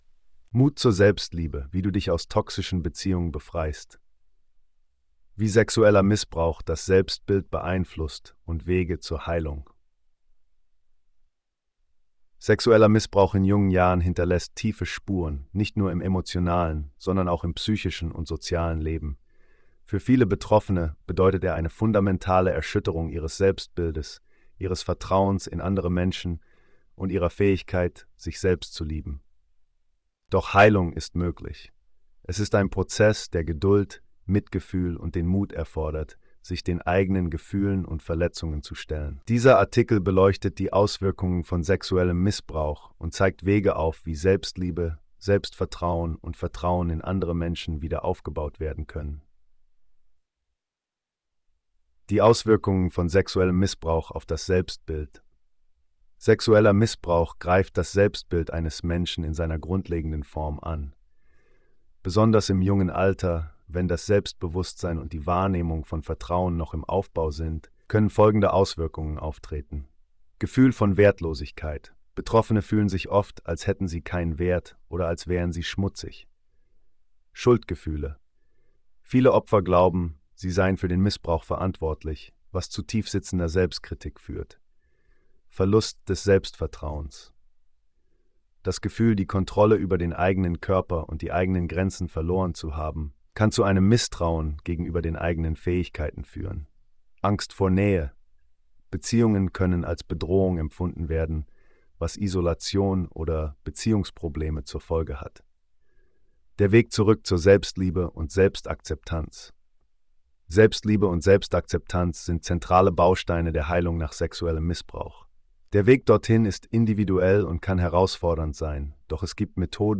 Hör dir die Audio-Version dieses Artikels an (generiert von KI).